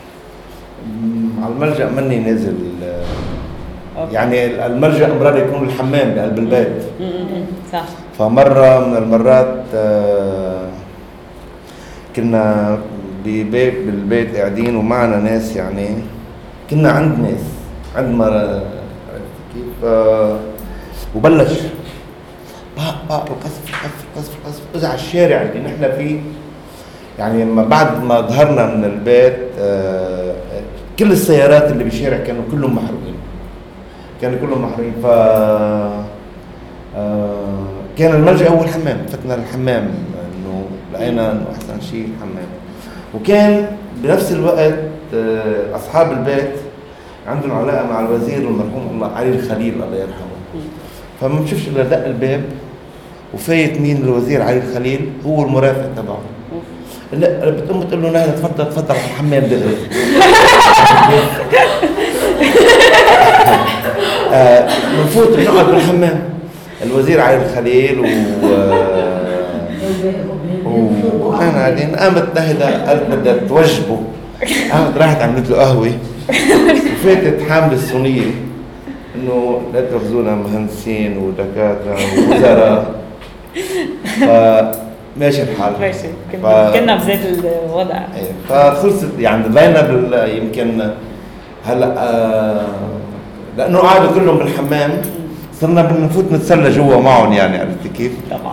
Following the film screenings, audience members were invited to come up and share their own experiences of sheltering during the war years.
Click below to listen to the stories of sheltering of our attendees…